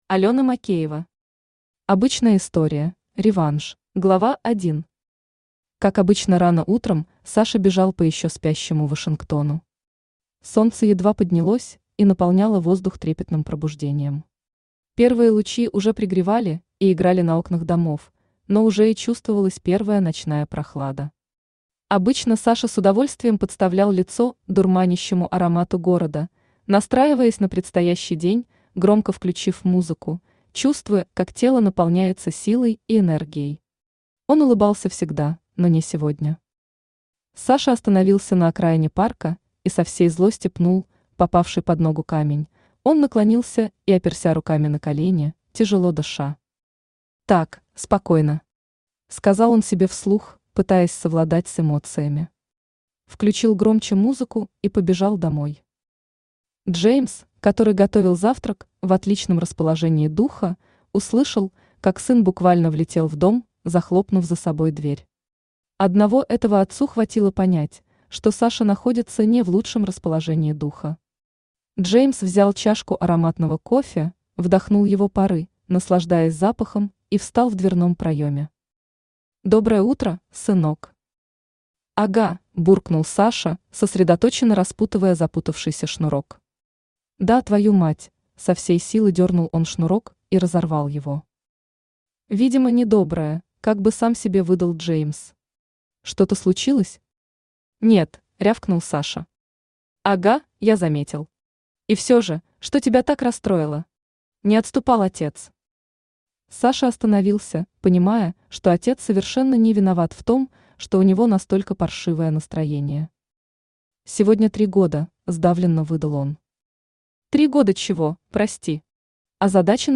Аудиокнига Обычная история: реванш | Библиотека аудиокниг
Aудиокнига Обычная история: реванш Автор Алёна Макеева Читает аудиокнигу Авточтец ЛитРес.